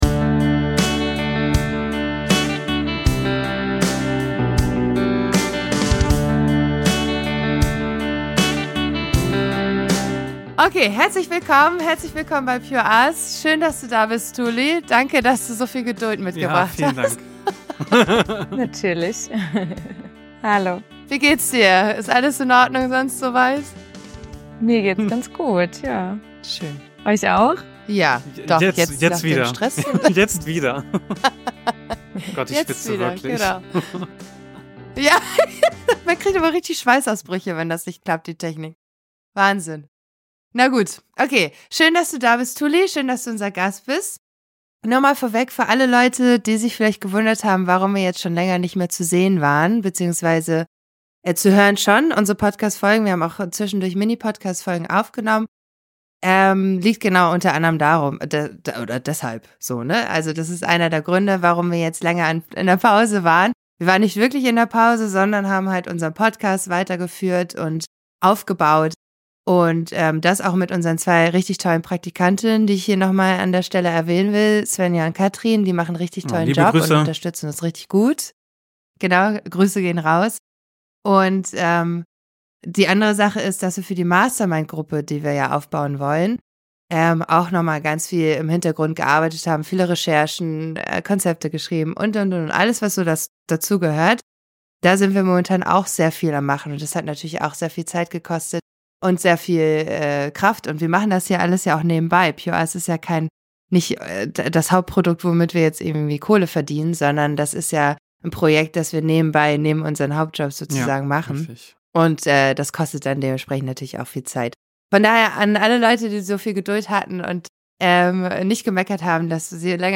Eine neue PureUs-Liveshow wurde aufgezeichnet.